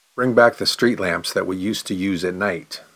Consonants-pronounce-1_fast.mp3